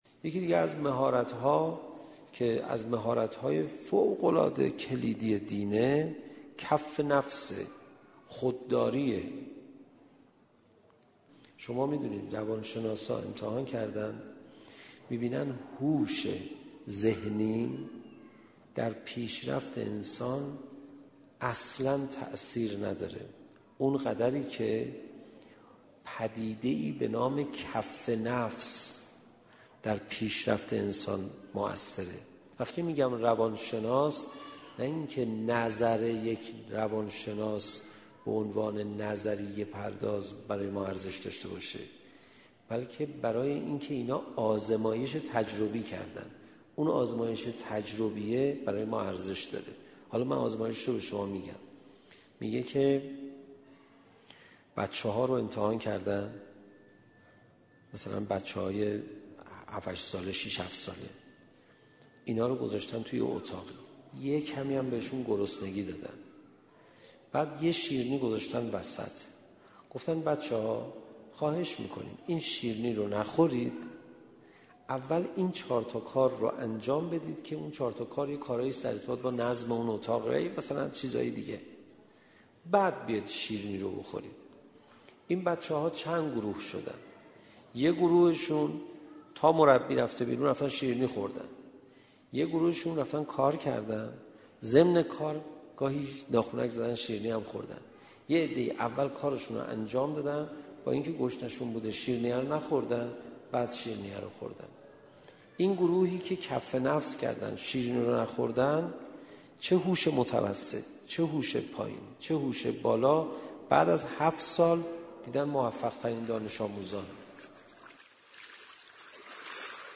منبر دو دقیقه ای/مهارت خودداری
منبر دو دقیقه ای